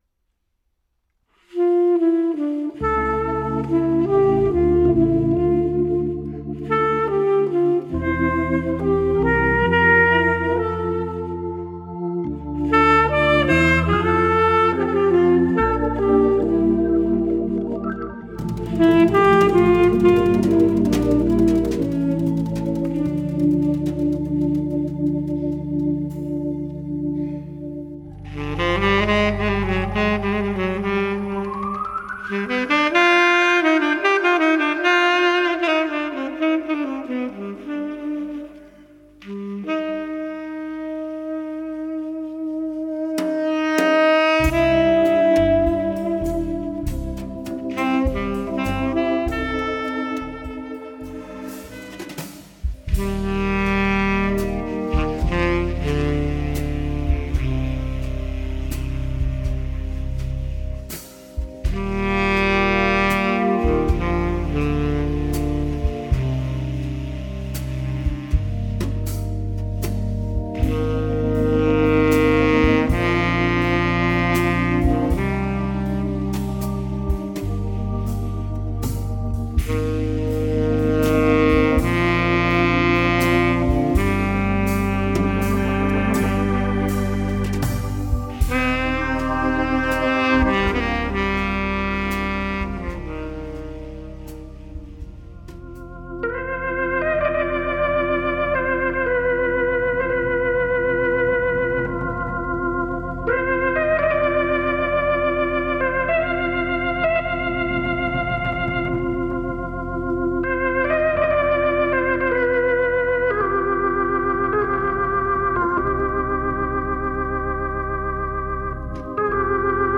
saxofon
trommerne